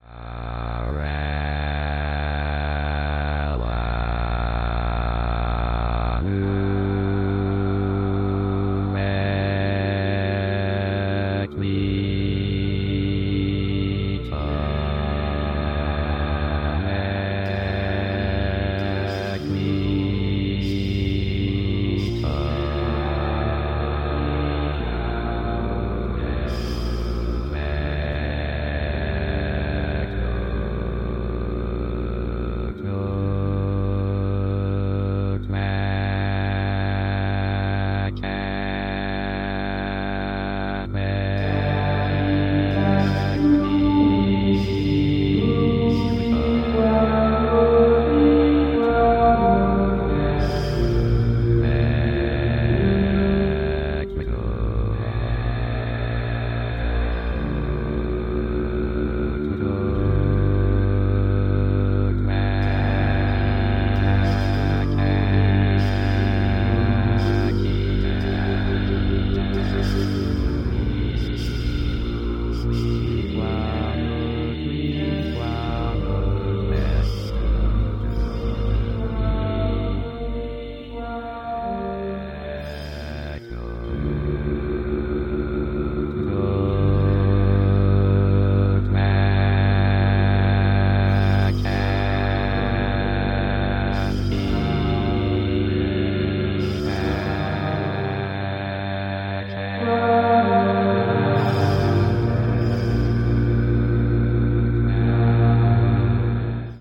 It is orchestral music produced with electronic devices
The vocals are Klingon and Chinese."